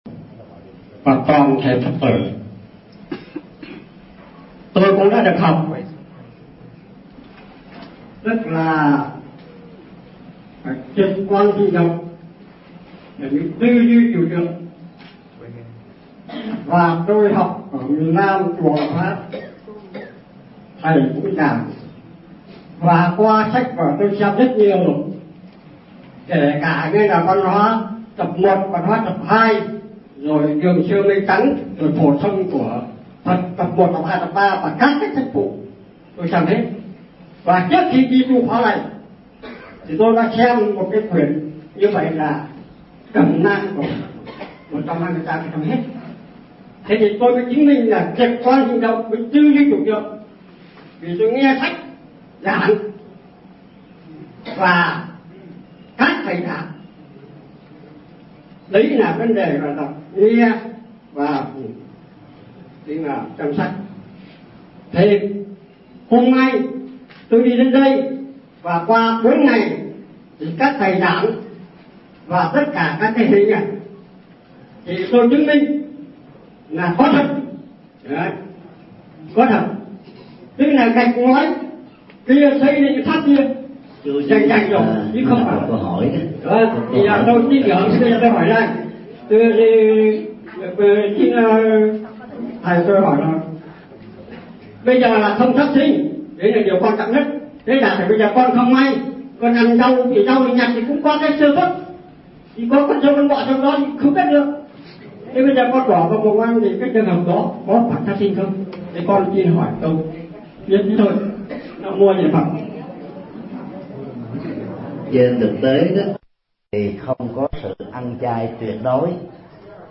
Vấn đáp: Giải thích ” Sự ăn chay tuyệt đối ”